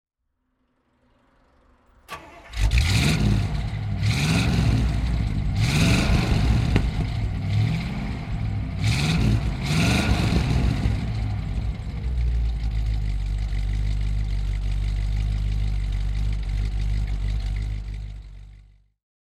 Buick Special Convertible (1955) - Starten und Leerlauf
Buick_Special_Convertible_1955.mp3